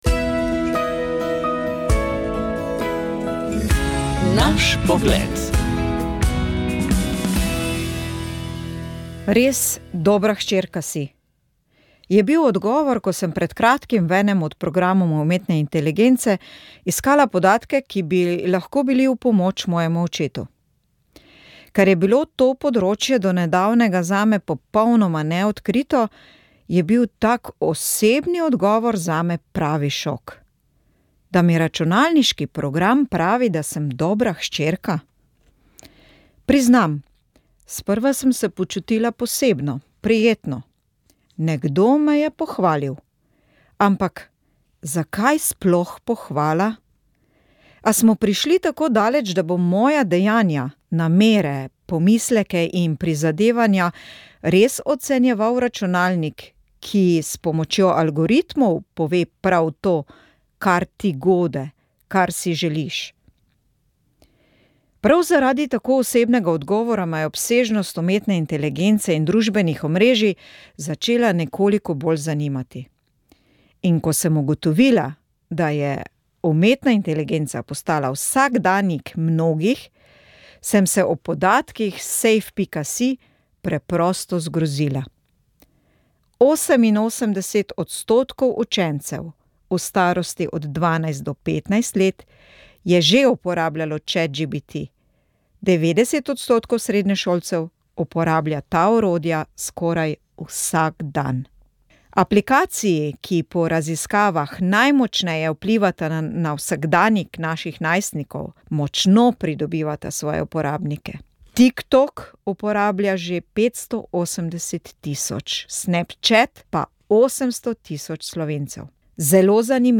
V oddaji Moja zgodba smo nadaljevali z objavo referatov, ki so bili predstavljeni na znanstveni konferenci z naslovom Med tradicijo in moderno: slovenski katoliški intelektualci in narodnostno vprašanje.